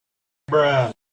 Bruh Sound Effect #2.mp3